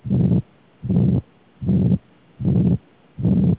Ventricular Septal Defect (.au, 29KB)